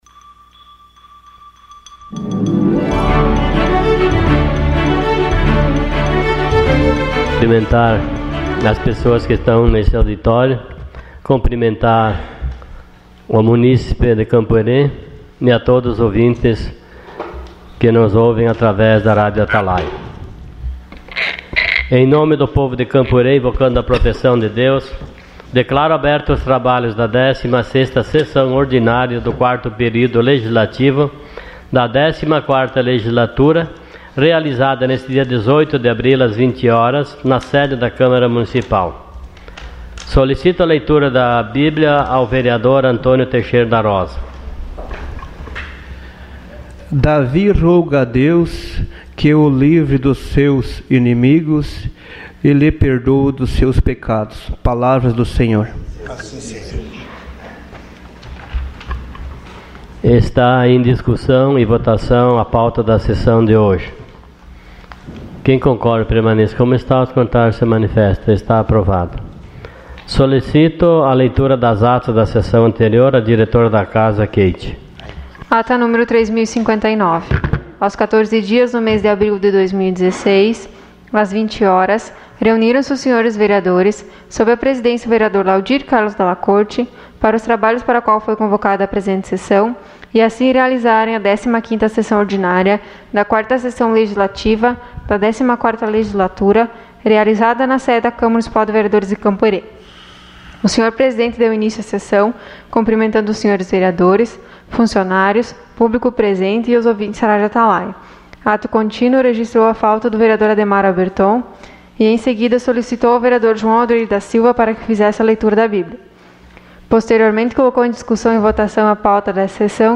Sessão Ordinária dia 18 de abril de 2016.